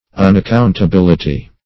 unaccountability - definition of unaccountability - synonyms, pronunciation, spelling from Free Dictionary
Search Result for " unaccountability" : The Collaborative International Dictionary of English v.0.48: Unaccountability \Un`ac*count`a*bil"i*ty\, n. The quality or state of being unaccountable.